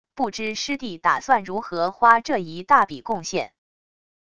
不知师弟打算如何花这一大笔贡献wav音频生成系统WAV Audio Player